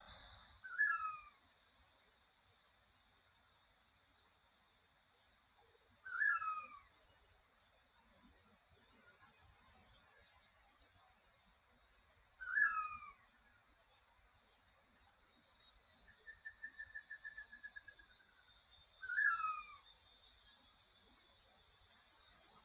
蓝头八色鸫鸣叫声